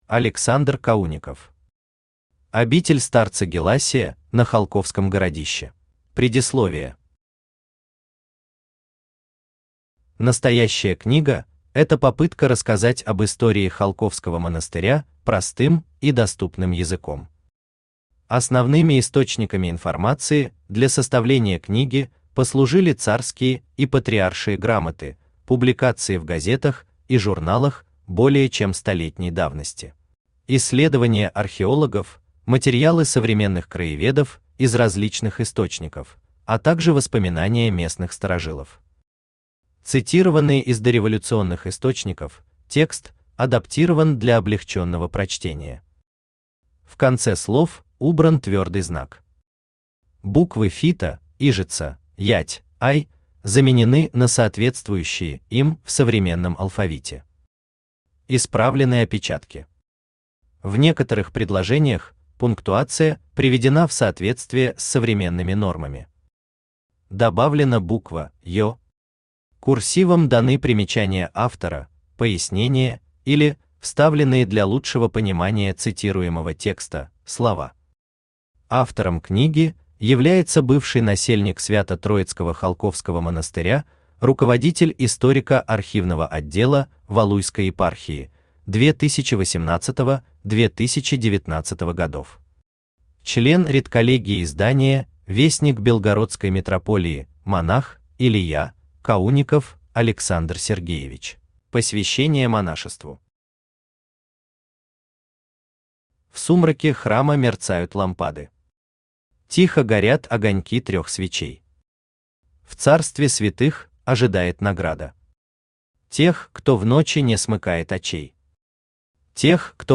Aудиокнига Обитель старца Геласия на Холковском городище Автор Александр Сергеевич Каунников Читает аудиокнигу Авточтец ЛитРес.